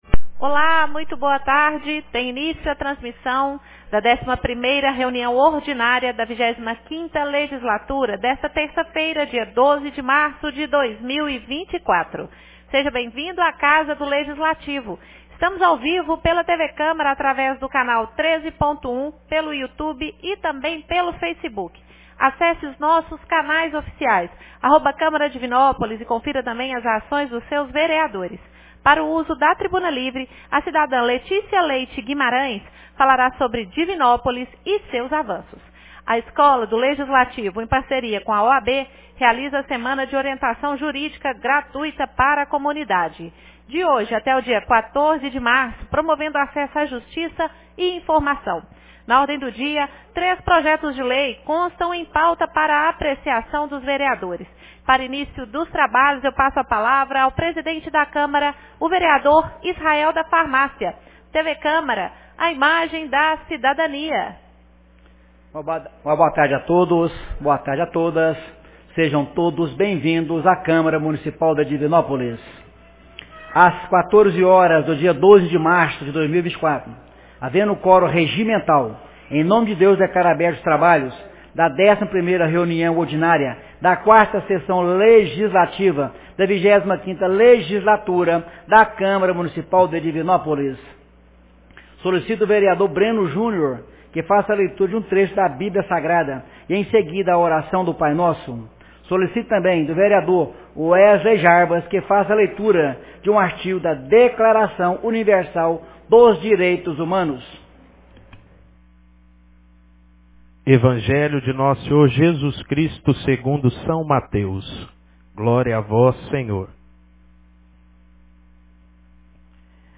11ª Reunião Ordinária 12 de março de 2024